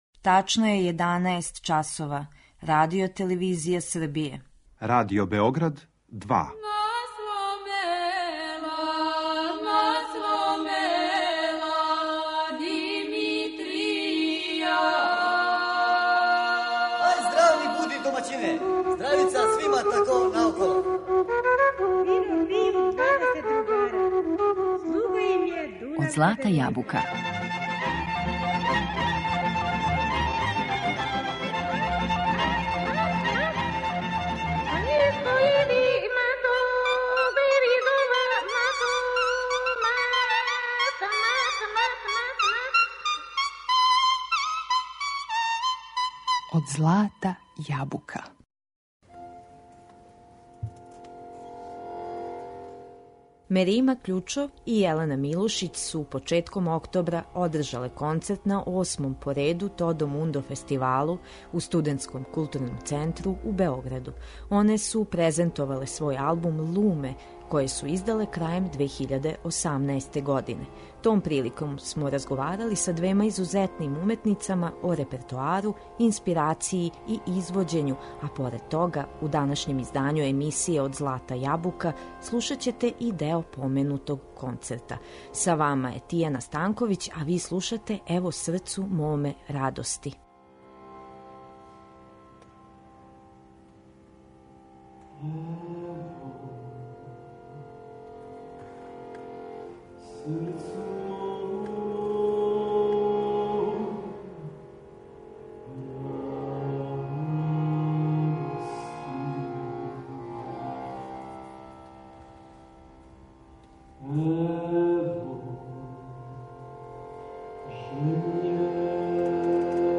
Том приликом смо разговарали са двема изузетним уметницама о репертоару, инспирацији и извођењу, а поред тога, у данашњем издању емисије Од злата јабука слушаћете и део поменутог концерта.